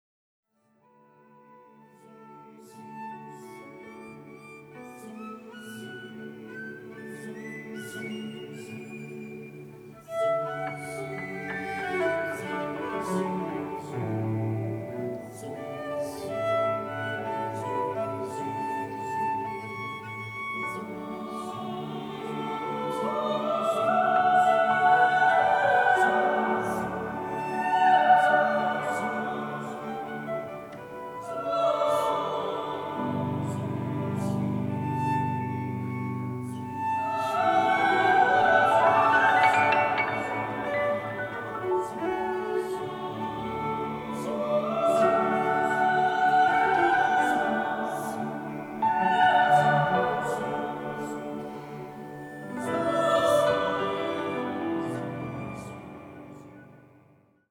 cello
recorded live in a concert in Krakow